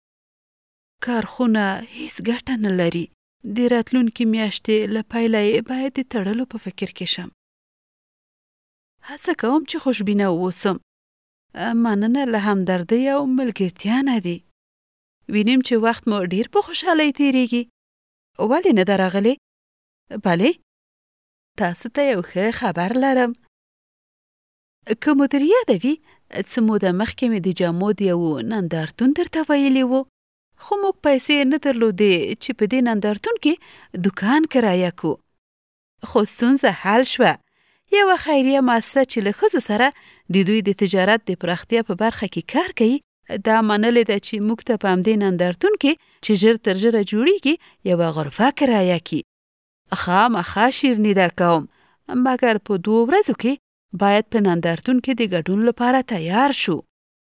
Professional Female Pashto Voice Samples
Our female Pashto voice artists offer a wide range of tones.
FEMALE_PASHTO-5.mp3